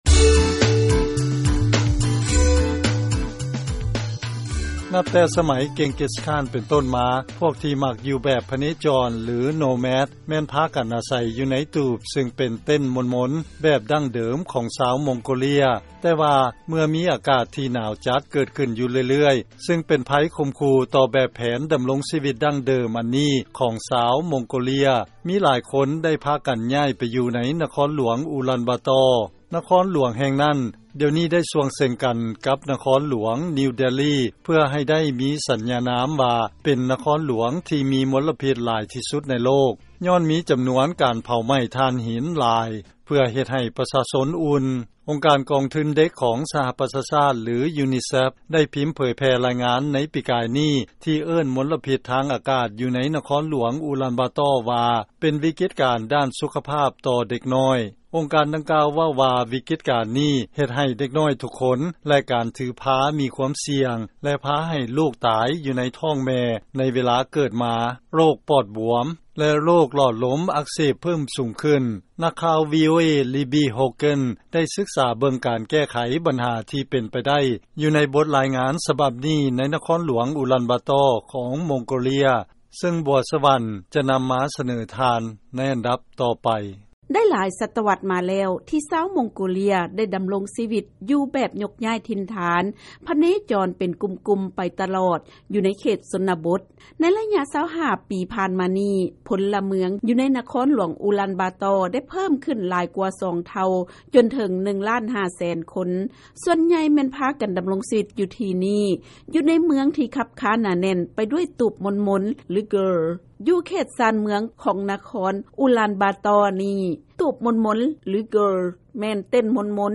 ເຊີນຟັງລາຍງານກ່ຽວກັບການໃຊ້ນະວັດຕະກຳໃໝ່ ເພື່ອແກ້ໄຂບັນຫາມົນລະພິດໃນມົງໂກເລຍ